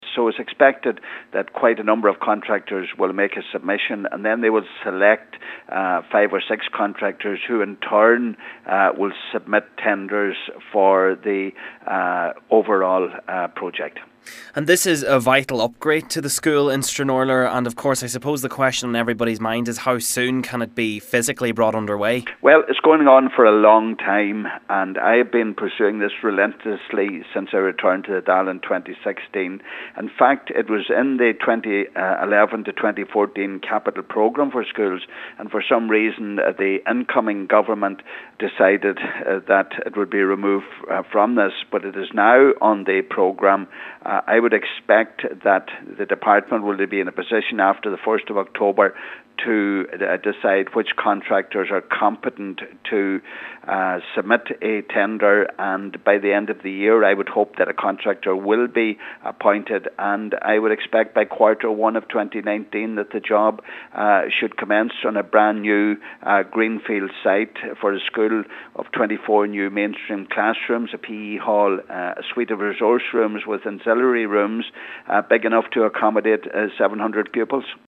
Donegal Deputy Pat the Cope Gallagher says getting the project under way has been a lengthy process: